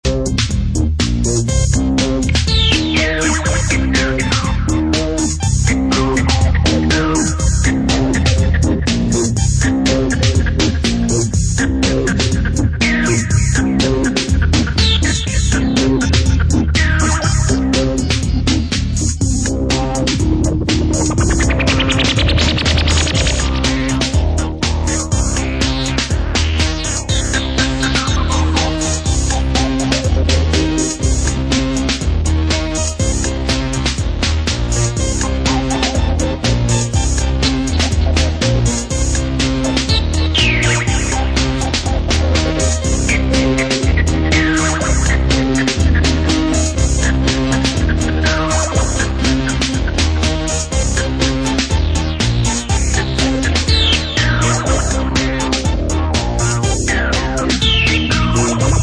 】UKメイドのアシッド・ハウスや初期ハウスをコンパイルした強力コンピレーション！